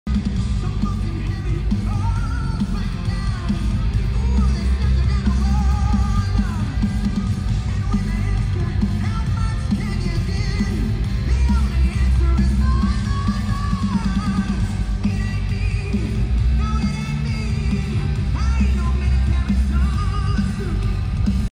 in Chicago